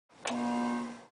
degauss.mp3